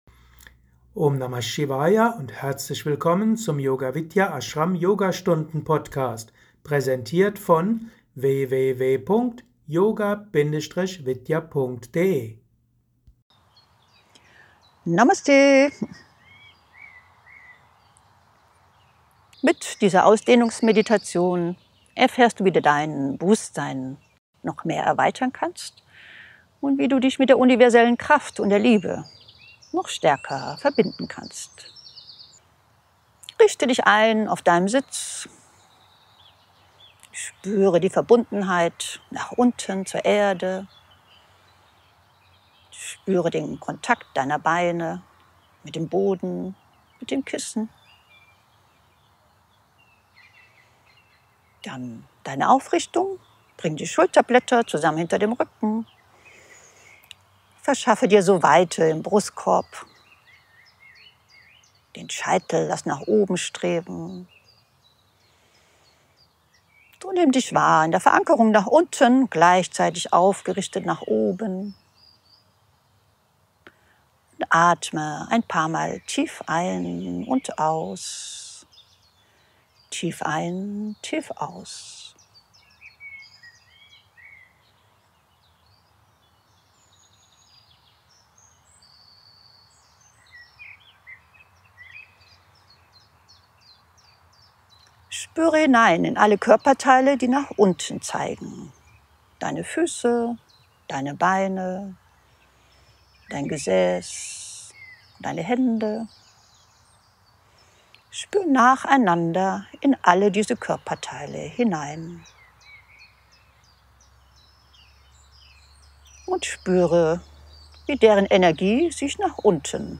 Spüre die Einheit – 20 Minuten Ausdehnungsmeditation ~ Special Yogastunden Podcast